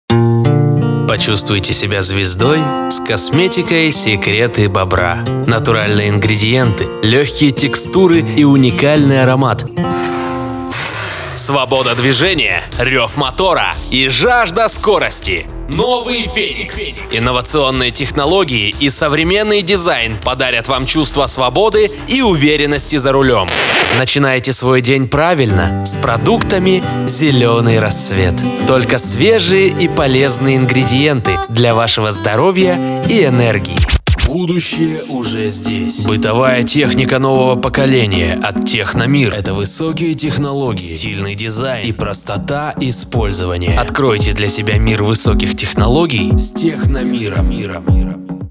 Конденсаторный микрофон Scarlett CM25 MkIII; Аудио-интерфейс: Lexicon Omega, Scarlett Focusrite Solo, Scarlett Focusrite 4i4 3Gen
Демо-запись №1 Скачать